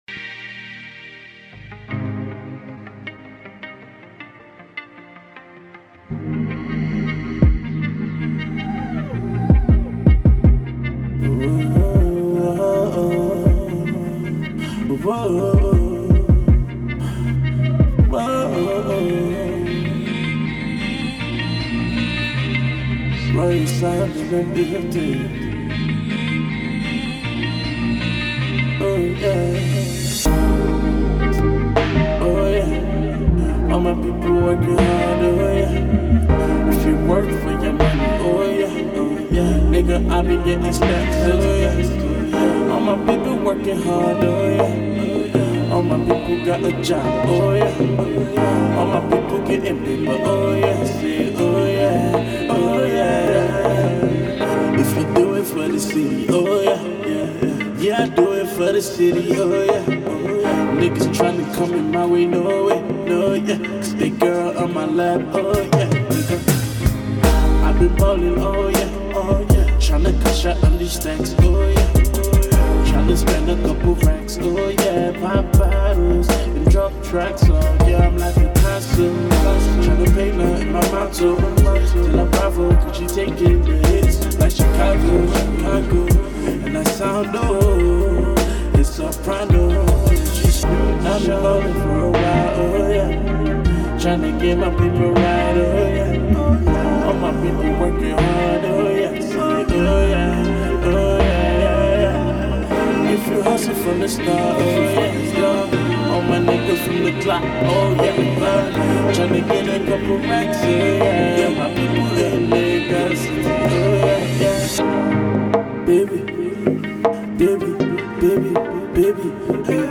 Smooth Ballad